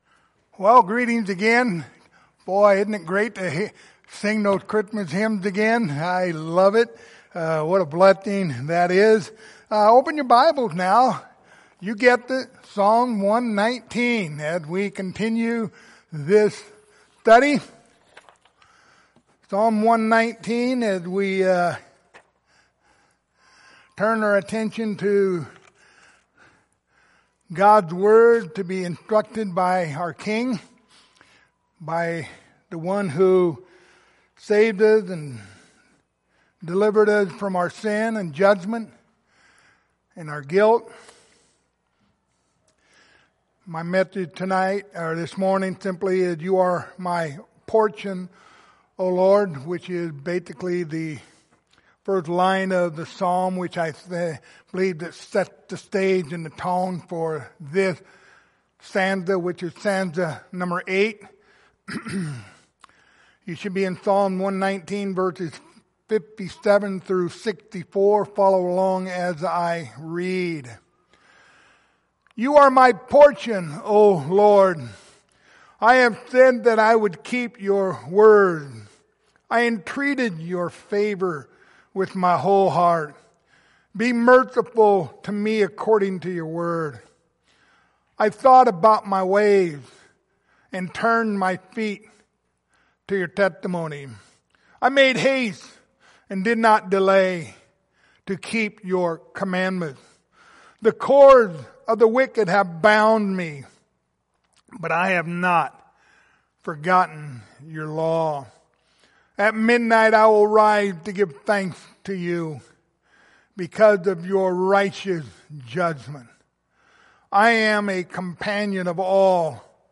Passage: Psalms 119:57-64 Service Type: Sunday Morning